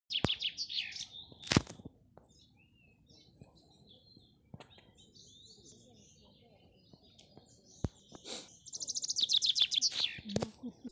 Chaffinch, Fringilla coelebs
StatusSinging male in breeding season